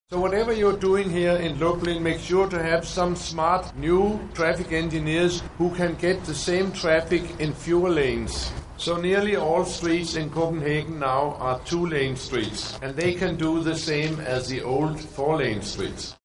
Podczas happeningu postanowił ją podsumować. Mówił o rowerzystach i o pieszych, na których trzeba zwracać szczególną uwagę w tworzeniu przestrzeni miejskich.